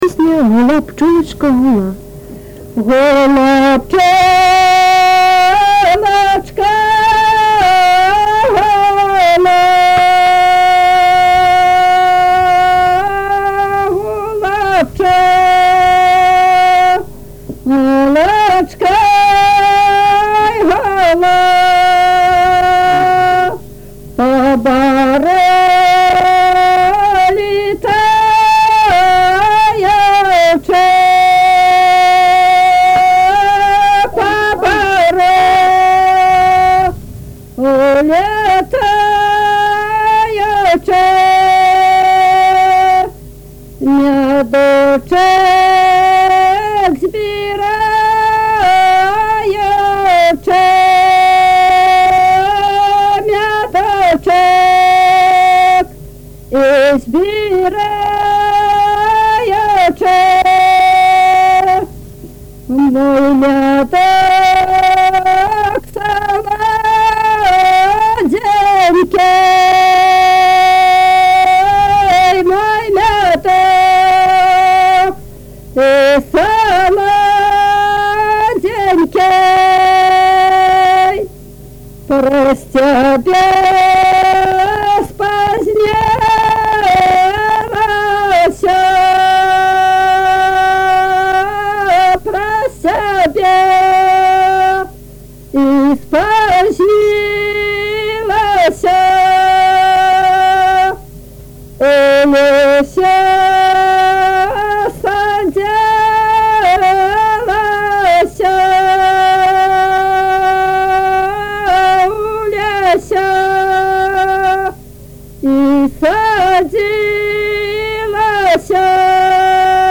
Тема: ЭБ БГУ::Беларускі фальклор::Каляндарна-абрадавыя песні::купальскія песні
Месца запісу: Узбішчы